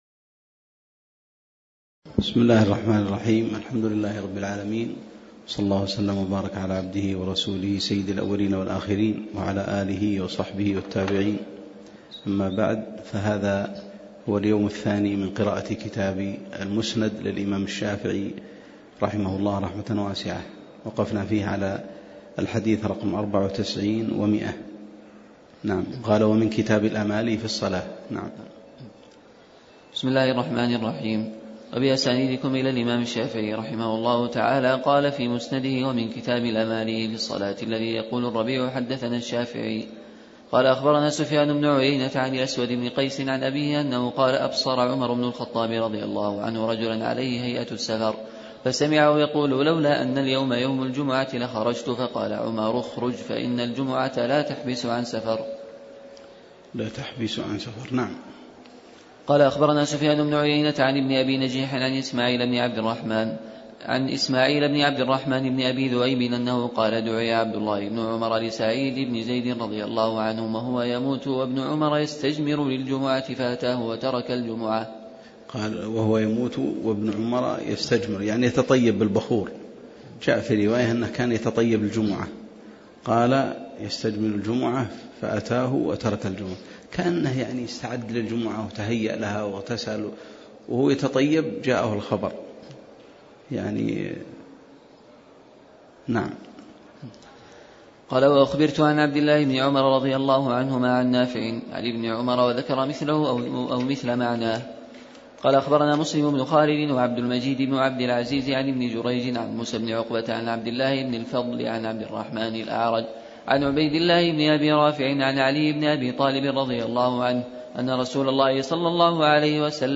تاريخ النشر ١٣ جمادى الآخرة ١٤٣٨ هـ المكان: المسجد النبوي الشيخ